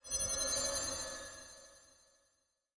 Uncanny Sound
神秘的声音